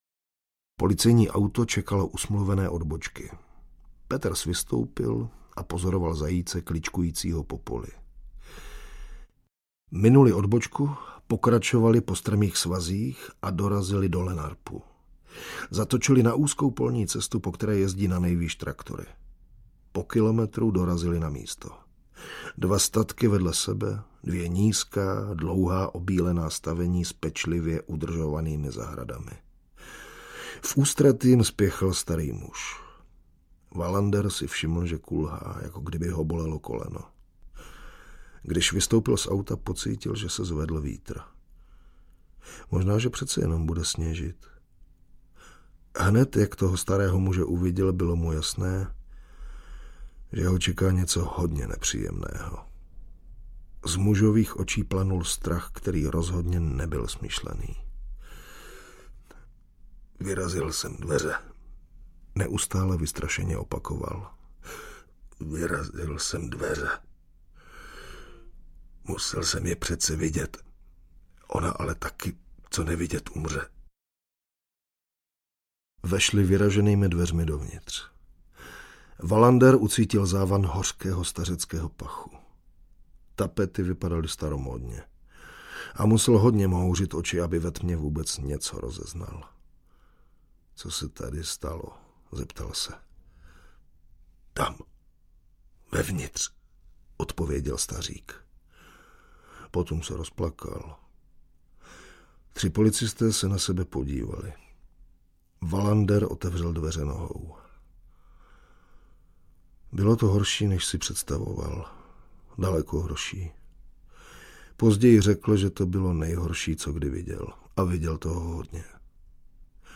Audiobook
Read: Jiří Vyorálek